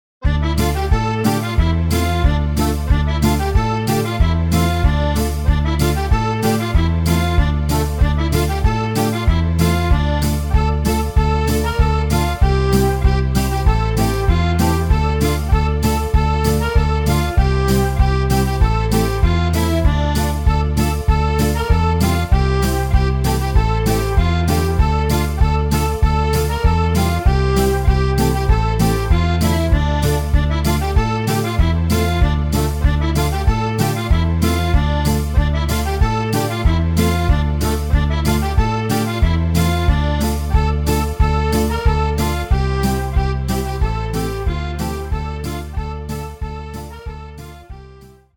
sans choeurs